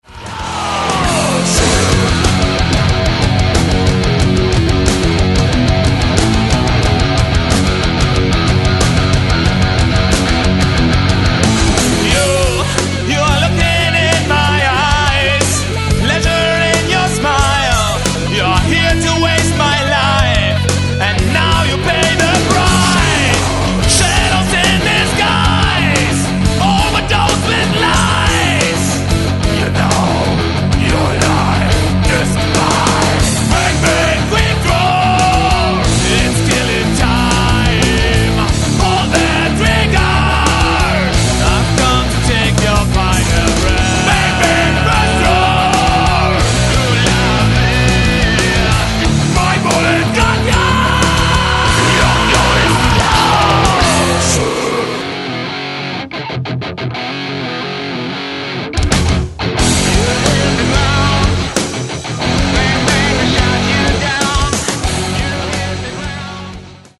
"Alternative Rock"
"Progressive Rock"
Demo Songs